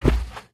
Minecraft Version Minecraft Version 25w18a Latest Release | Latest Snapshot 25w18a / assets / minecraft / sounds / mob / polarbear / step2.ogg Compare With Compare With Latest Release | Latest Snapshot
step2.ogg